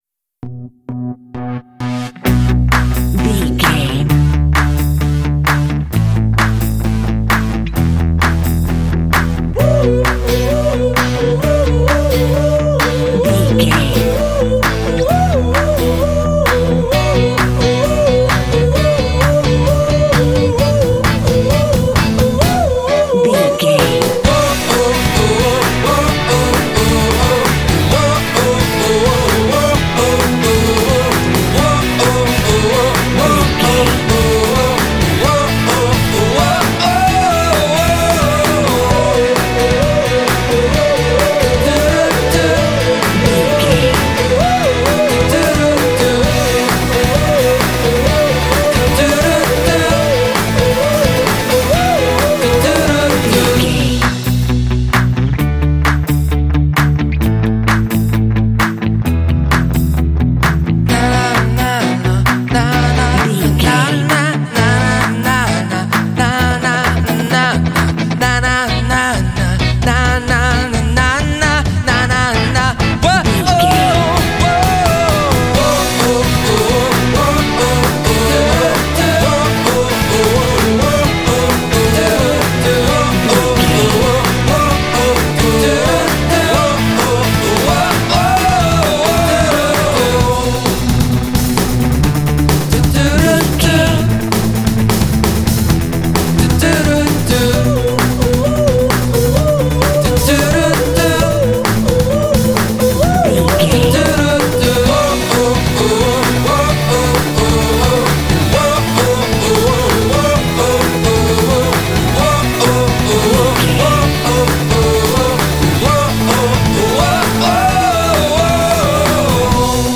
Aeolian/Minor
energetic
driving
optimistic
lively
repetitive
drums
bass guitar
electric guitar
vocals